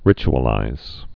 (rĭch-ə-līz)